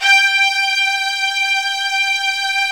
55u-va12-G4.aif